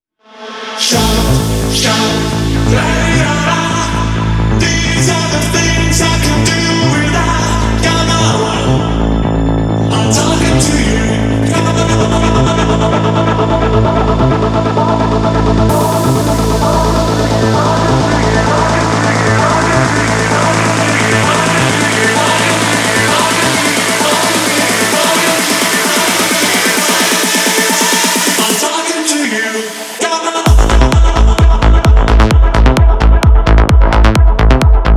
Dance Electronic
Жанр: Танцевальные / Электроника